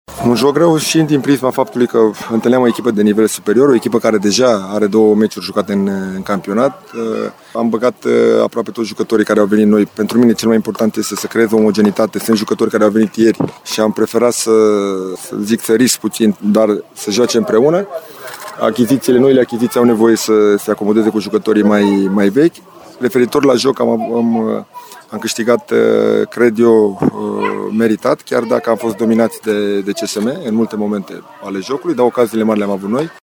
Antrenorul timișorenilor, Dan Alexa, a riscat mult, introducând ca titulari pe doi jucători care au venit la echipă doar săptămâna aceasta: